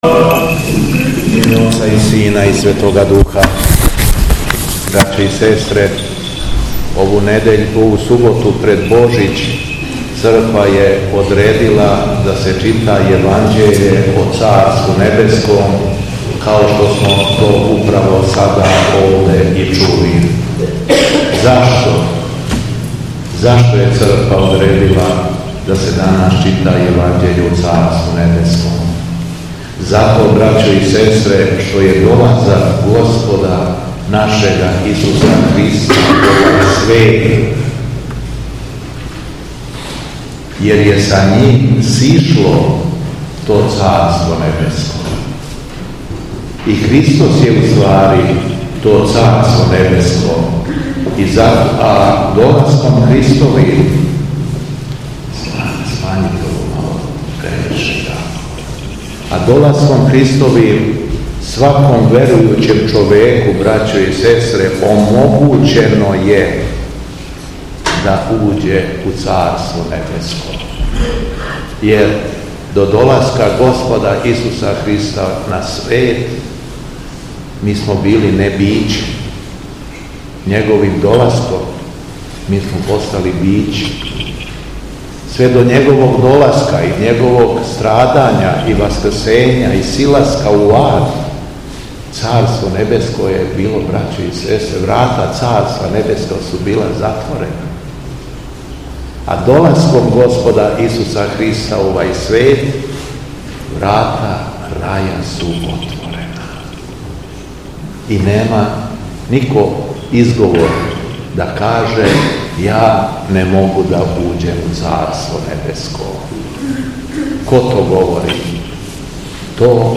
У суботу 4. јануара 2025. године, на дан молитвеног сећања на Свету великомученицу Анастасију и Преподобног Никифора Лепрозног, Његово Високопреосвештенство Архиепископ крагујевачки и Митрополит шумадијски Господин Јован служио је Свету архијерејску Литургију у цркви Преподобне матере Параскеве у Ја...
Беседа Његовог Високопреосвештенства Митрополита шумадијског г. Јована